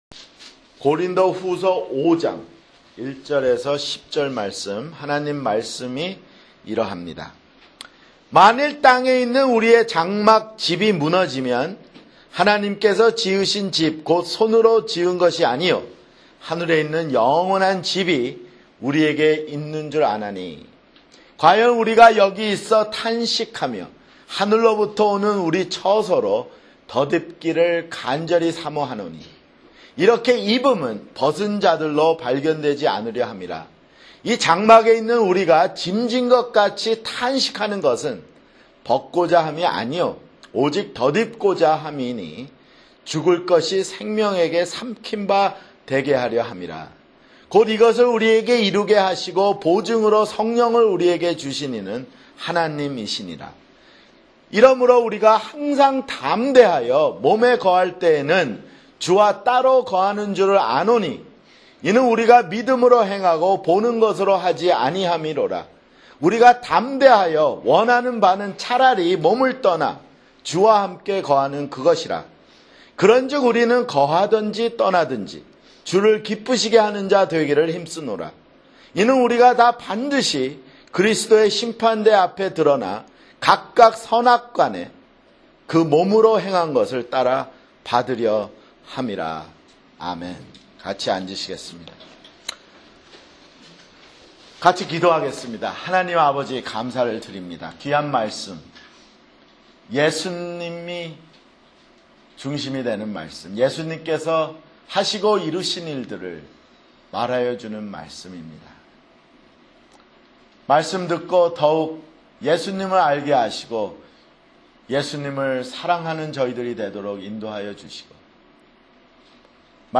[주일설교] 고린도후서 (25)